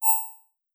SciFiNotification3.wav